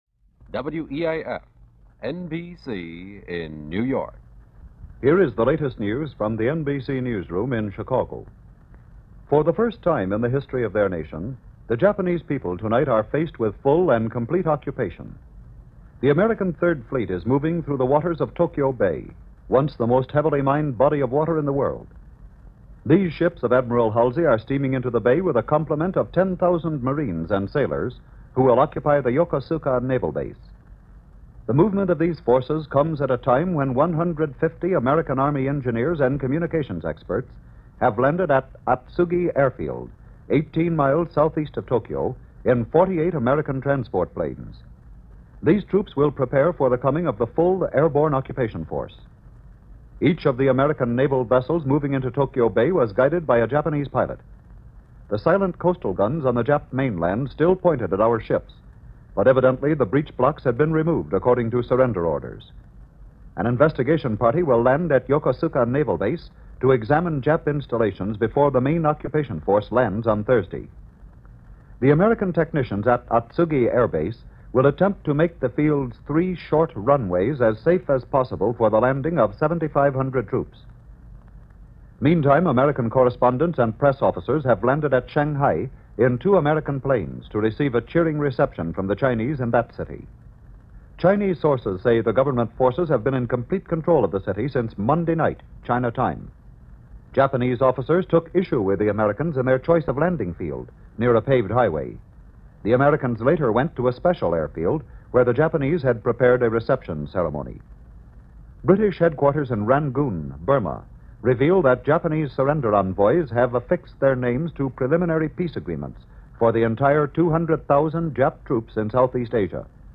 – NBC Radio News – August 27, 1945 – Gordon Skene Sound Collection –